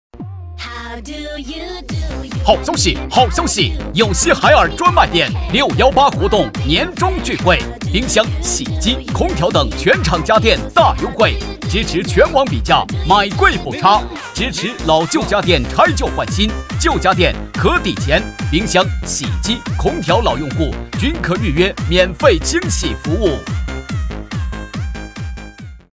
男46号配音师 点击进入配音演员介绍 进入后下滑播放作品集↓↓↓ 配音演员自我介绍 B级配音师，2008年毕业于成都理工大学广播影视学院 播音与主持艺术系 本科 2012年取得中国传媒大学MFA艺术硕士学位 拥有播音教学10年经验 从事配音行业8年 声音 庄重 自然 能表现真实 老师宣言:音尚生活，精彩世界. 代表作品 Nice voices 促销 广告 专题片 方言 促销-男46-海尔专卖店（激情）.mp3 复制链接 下载 促销-男46-爱玛电动车（喜悦）.mp3 复制链接 下载 促销-男46-豪爵摩托（年轻）.mp3 复制链接 下载